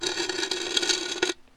sounds_scrape_05.ogg